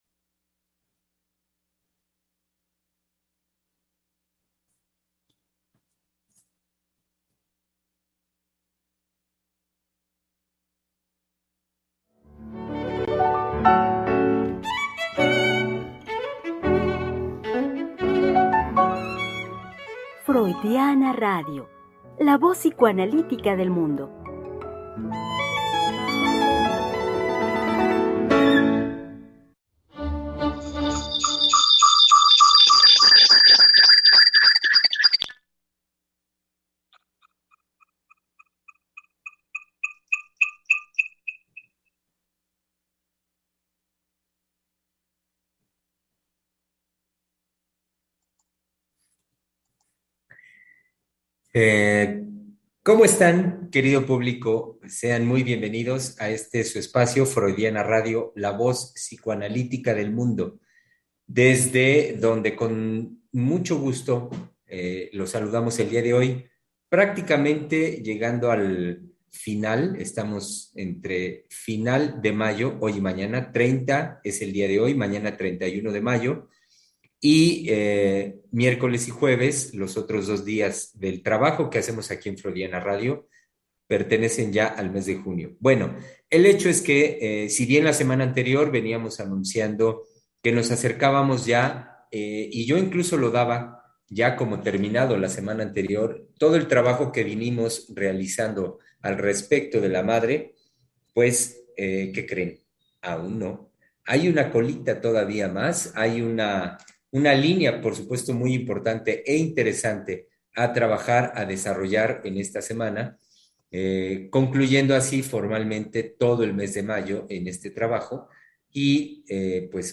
Programa transmitido el 30 de mayo del 2022.